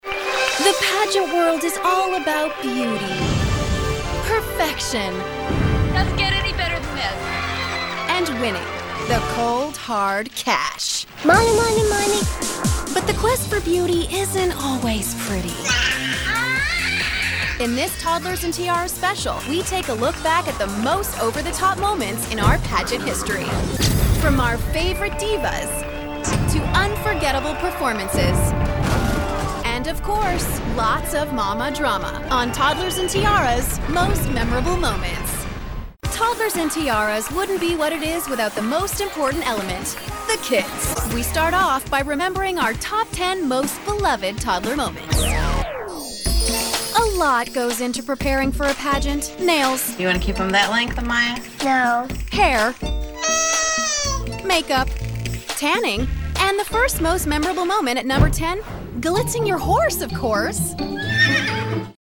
She has voiced everything from promos to cartoons to video games and narration.
Narration - EN